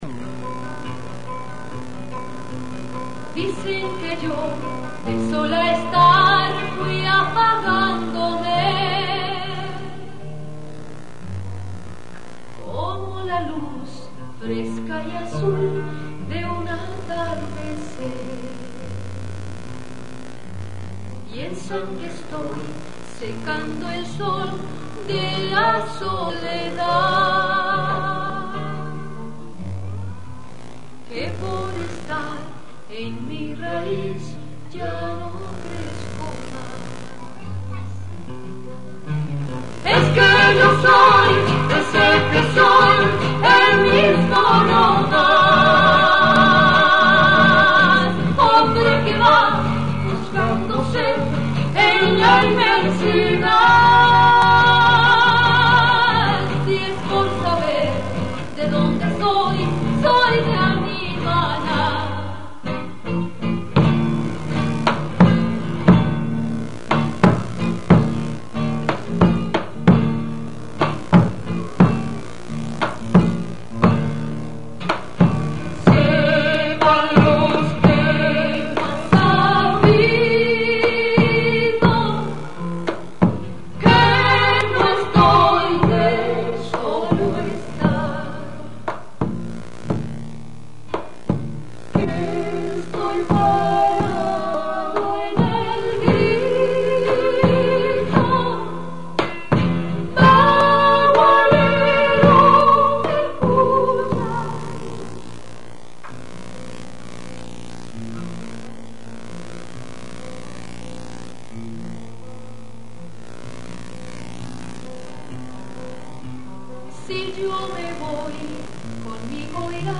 Grabación casera, 8 de julio de 1980
combinación de vidala y trote